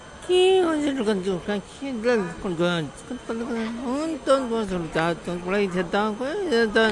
Vietnam Texture » Malaysia DT TE01 NoiBaiInternationalAirportTransferLounge
标签： Texture Airport NoiBai
声道立体声